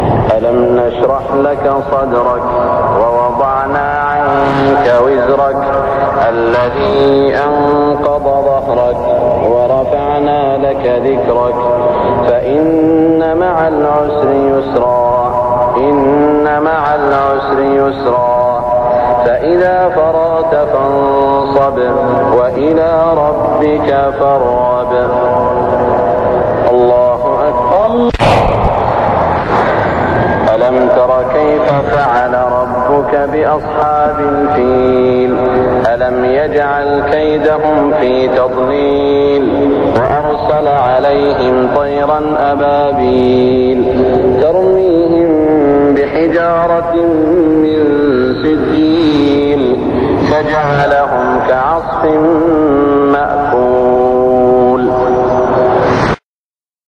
صلاة المغرب 1416هـ سورتي الشرح و الفيل > 1416 🕋 > الفروض - تلاوات الحرمين